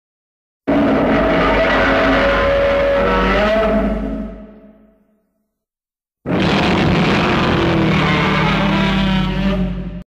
Godzilla Roar (1954).mp3